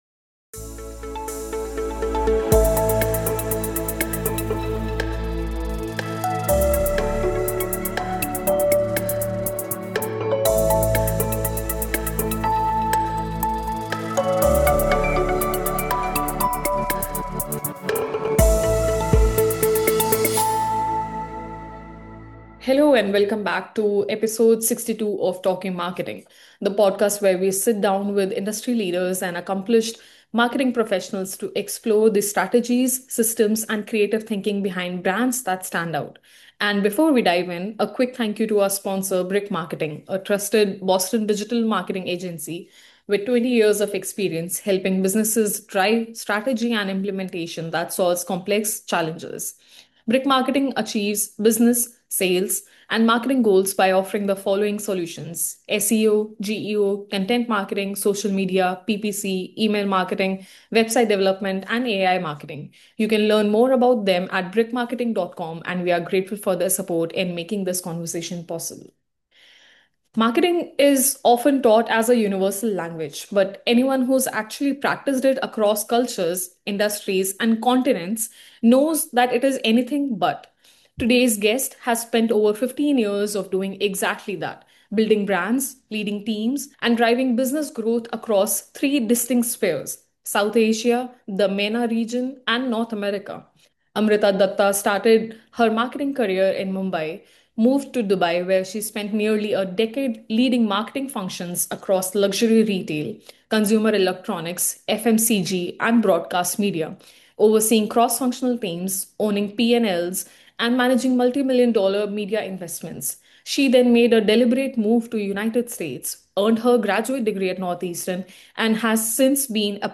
The kind of conversation that reminds you how much there still is to learn.